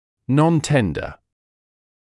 [ˌnɔn’tendə][ˌнон’тэндэ]не болезненный